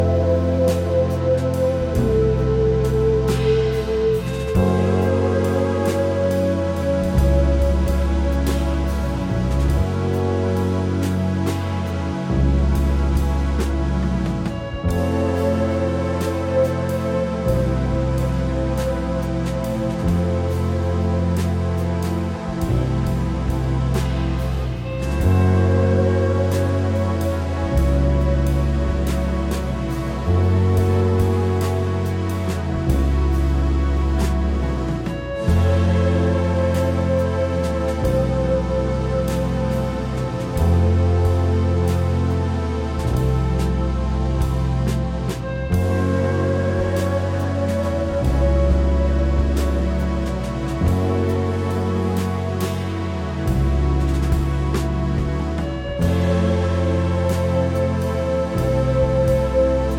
今回は、スイスの高地にあり、高級スキーリゾート地として知られる村レザンで6日間かけて録音したという作品。
清涼感や浮遊感を纏ったクールな現代ジャズ/フュージョンを繰り広げています。